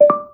start_sound.wav